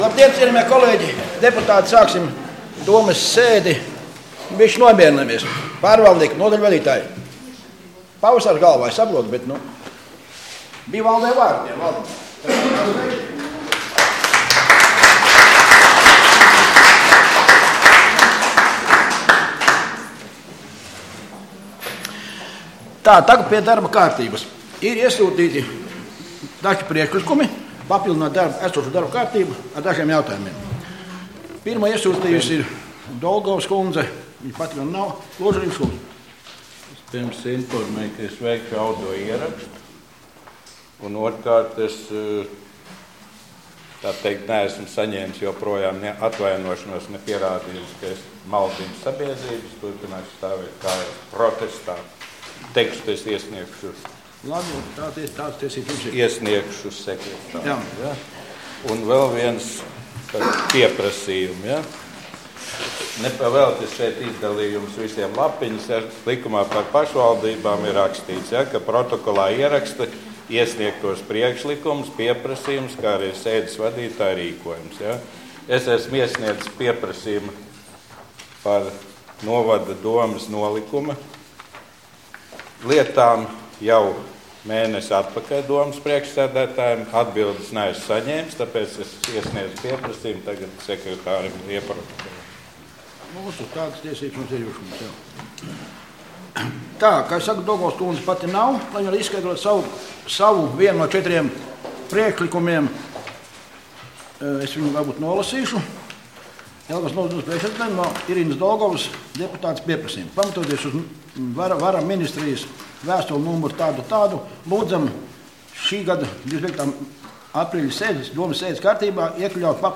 Domes sēde Nr. 5